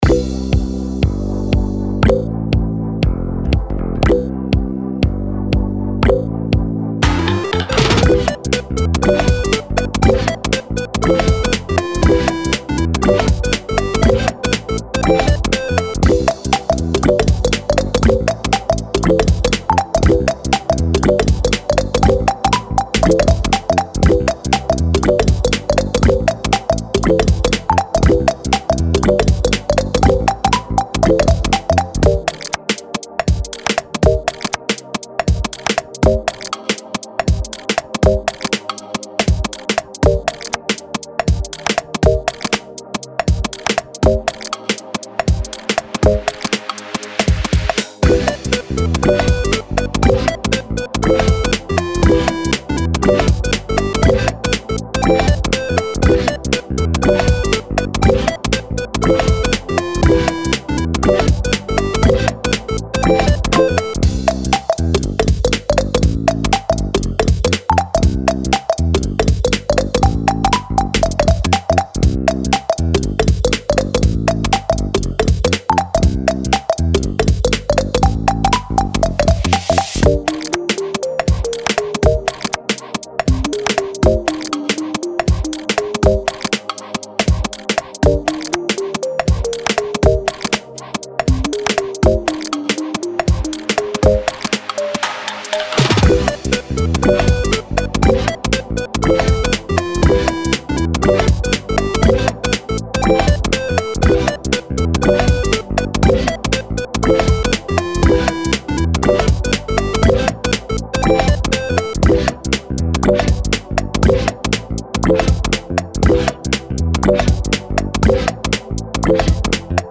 10分ループVer.